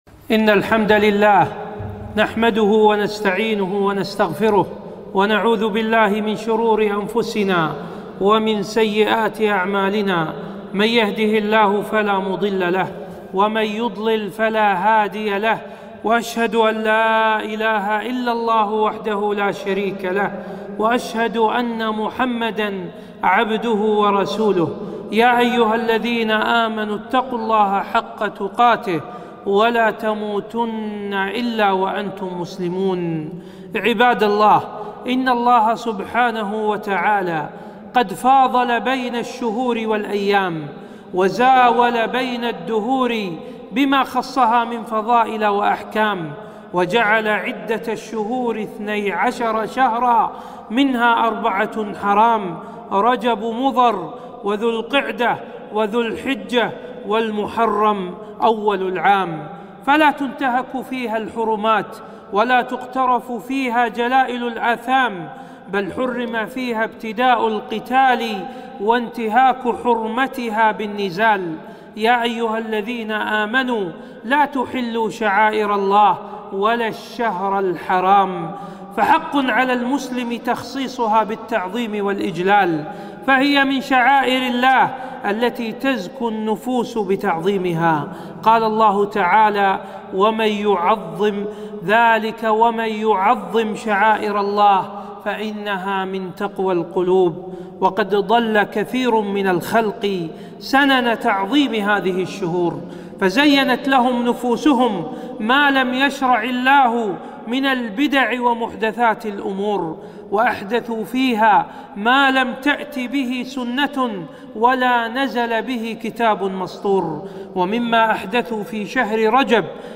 خطبة - التحذير من بدع شهر رجب